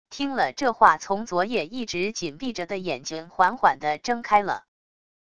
听了这话从昨夜一直紧闭着的眼睛缓缓的睁开了wav音频生成系统WAV Audio Player